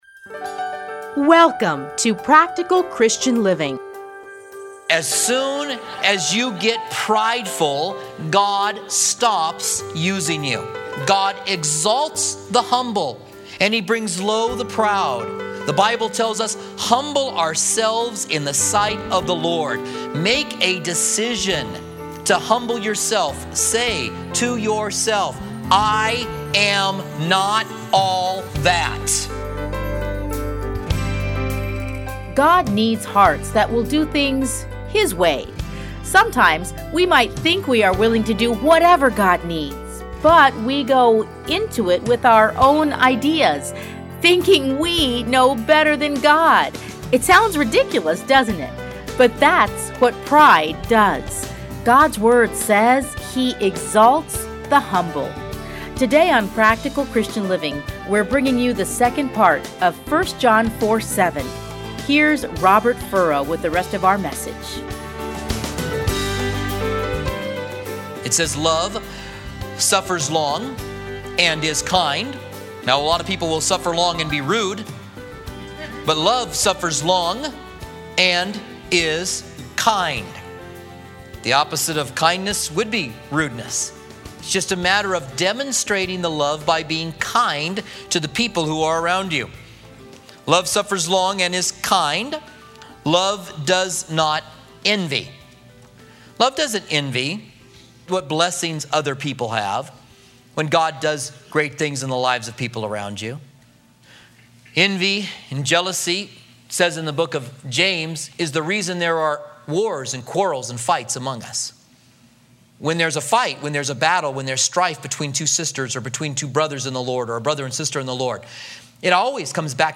Listen here to his studies in the book of 1 John.